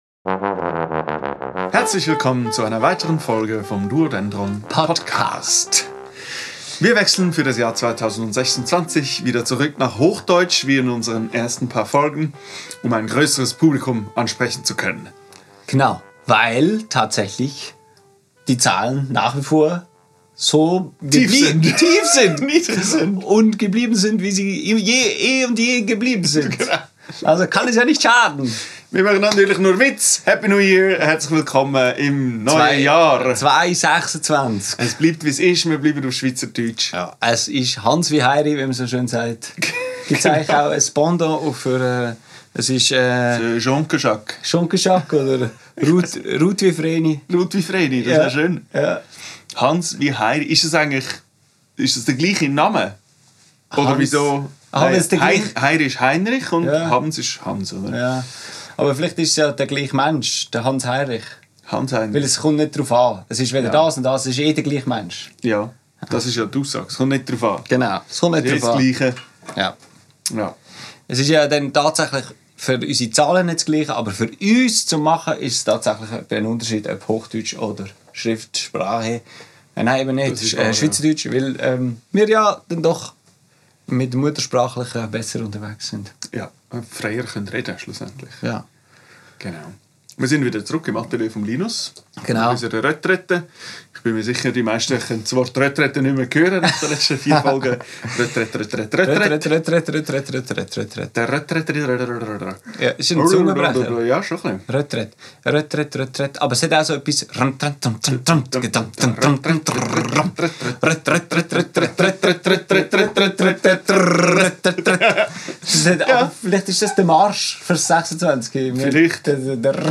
Aufgenommen am 30.12.2025 im Atelier